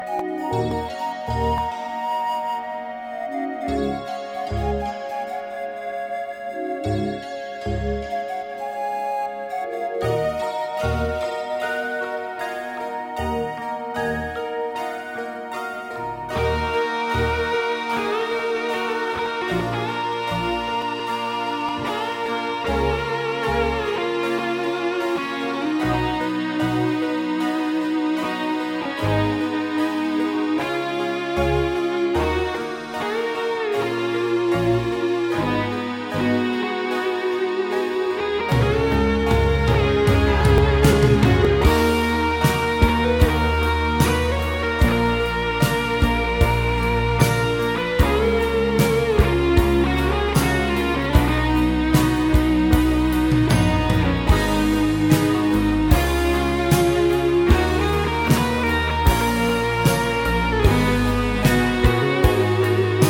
این اهنگ قطعه ای بدون شعر
Progressive Rock